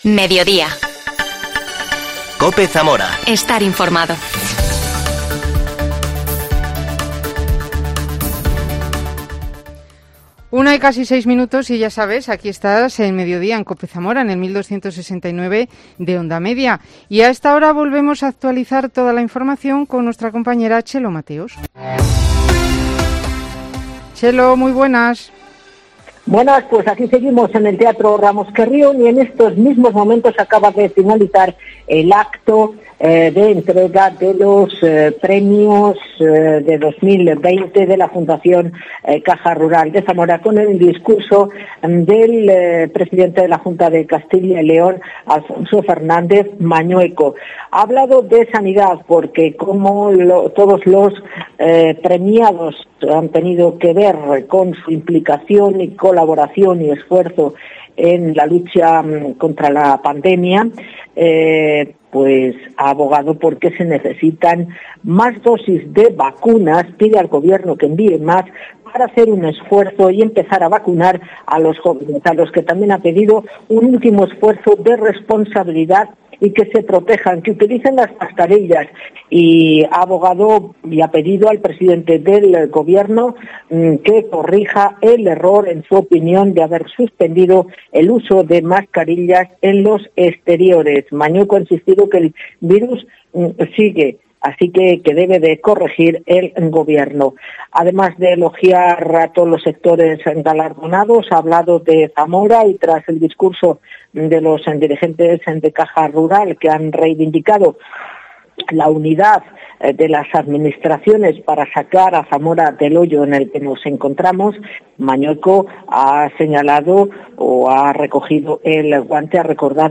AUDIO: La Concejala de Cultura del Ayuntamiento de Zamora, María Eugenia Cabezas habla del verano cultural